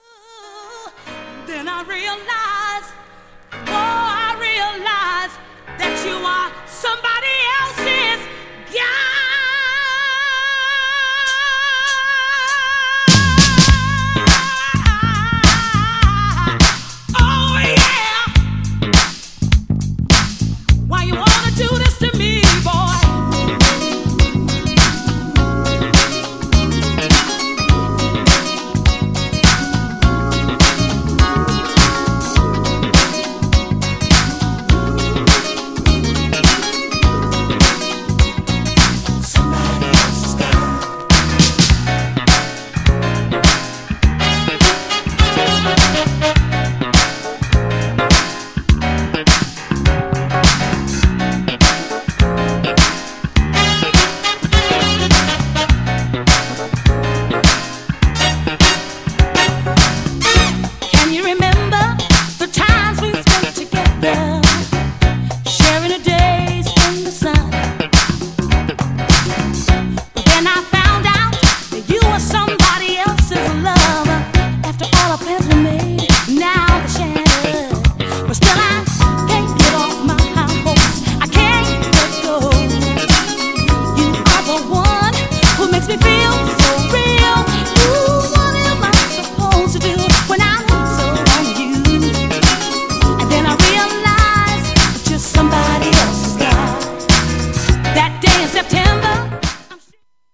GENRE Dance Classic
BPM 101〜105BPM
HAPPY系サウンド
オールドスクール # キャッチー # ヴォコーダー入り